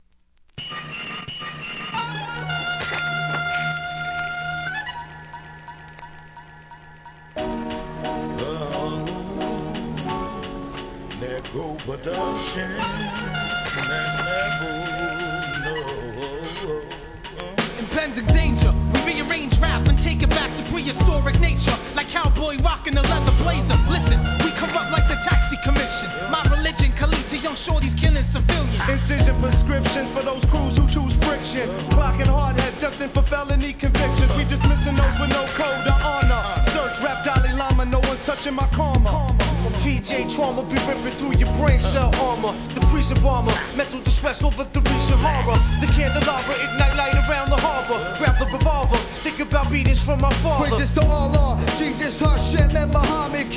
1. HIP HOP/R&B
'90s アンダーグランドN.Y.賛歌!!